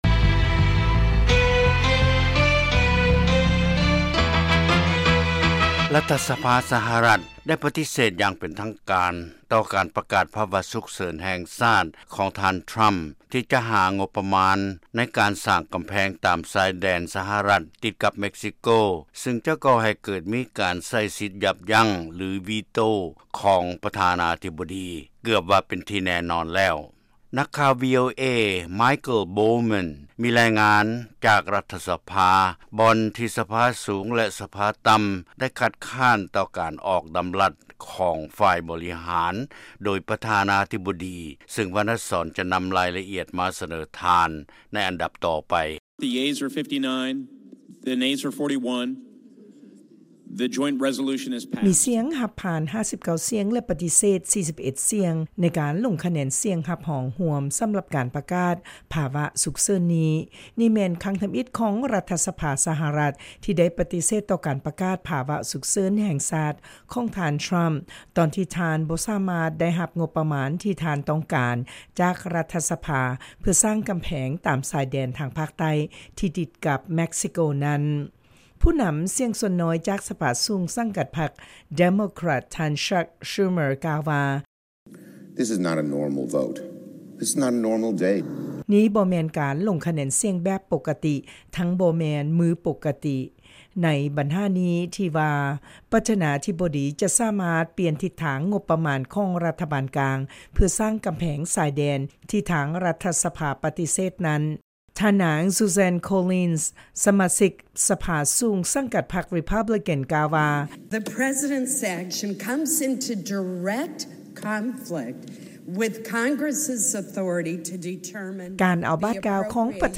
ລາຍງານກ່ຽວກັບການລົງຄະແນນສຽງຮັບຮອງຮ່ວມ ສຳລັບການປະກາດພາສະວຸກເສີນແຫ່ງຊາດຂອງທ່ານທຣຳ